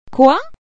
Quoi ?   uh-ee